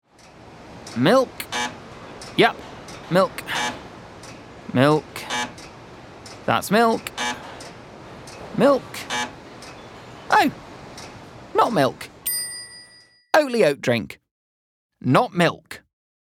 Oatly - Happy, Clear, Characterful